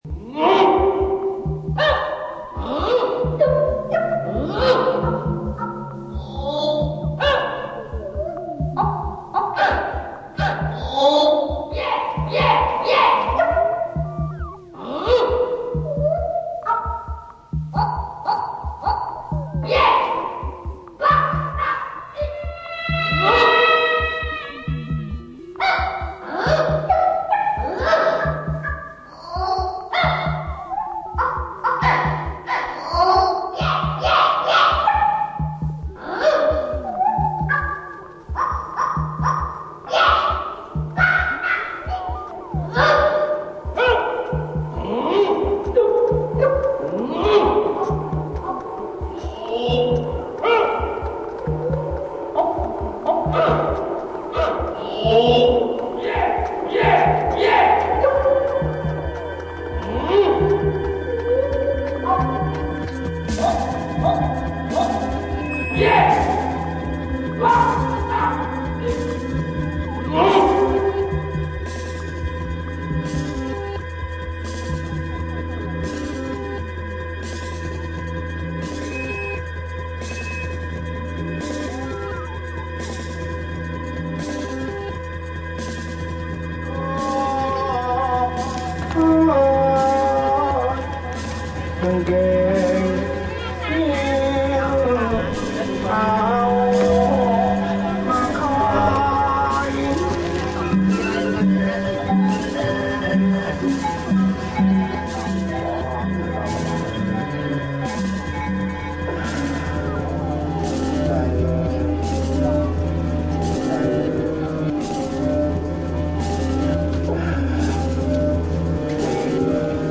Posted on March 6th, 2005 by s&S. Categories: *Royal Oakland Gramophone Co., collage, live-mix.
Sunday, March 6th, 2005 – 8pm LIVEmix: Shirley & Spinoza Sunday drive through another sound maze.